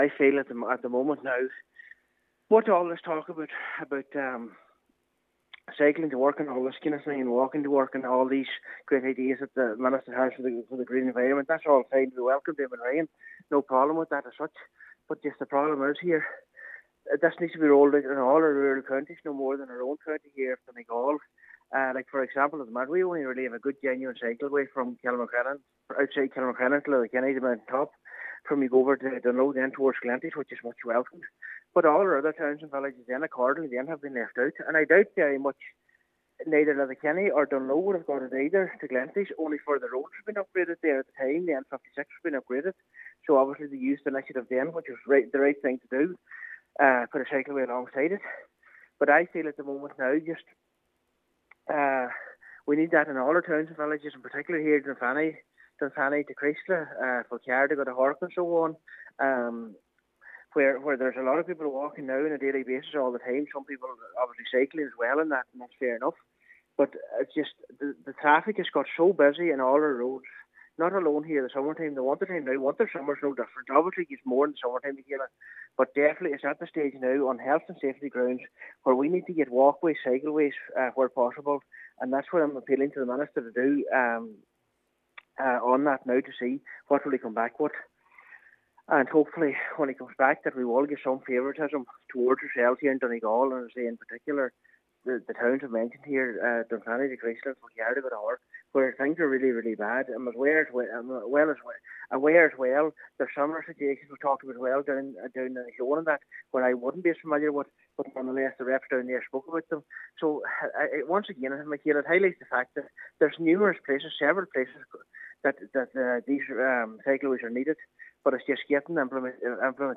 Councillor McClafferty says action must be taken: